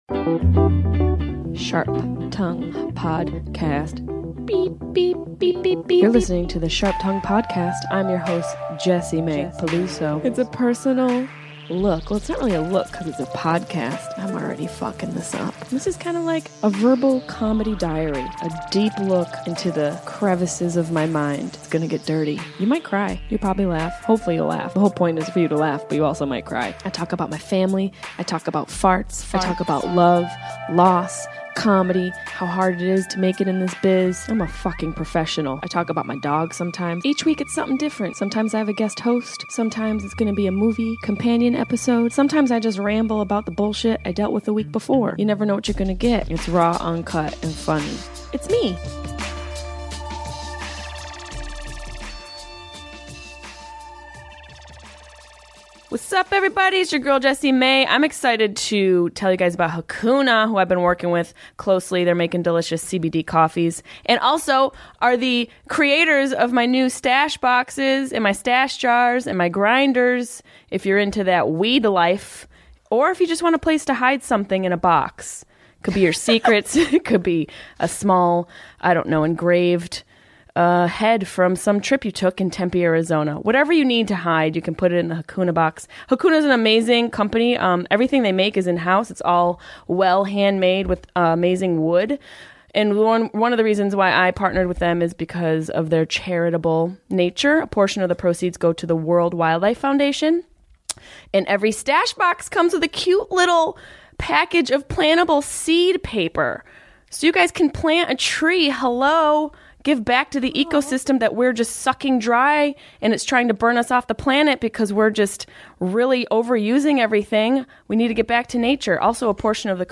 This week we discuss a serious subject matter like epilepsy by adding some humor and insight.